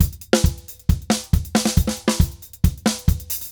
Drums_Merengue 136-2.wav